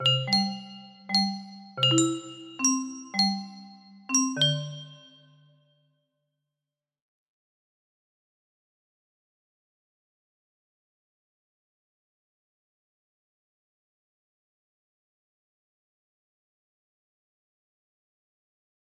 music box melody